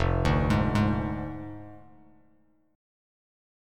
Fm6 Chord
Listen to Fm6 strummed